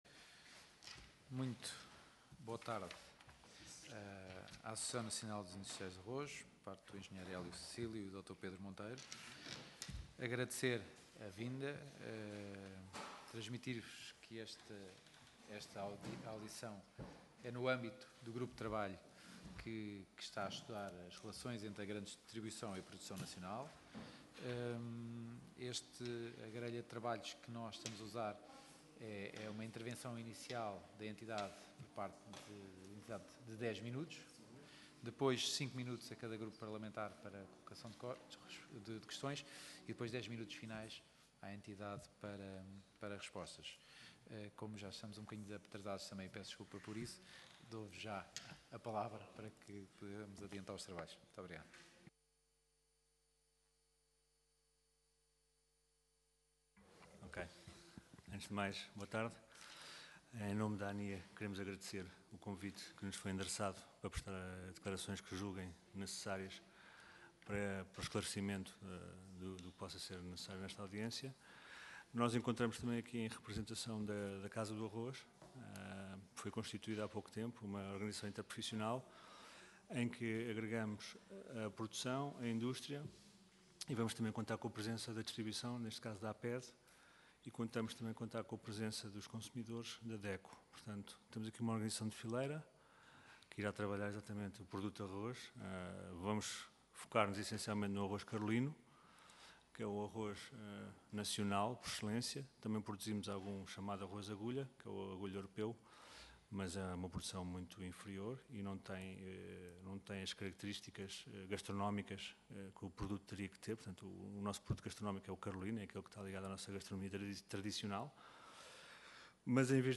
Grupo de Trabalho - Grande Distribuição e Produção Nacional Audição Parlamentar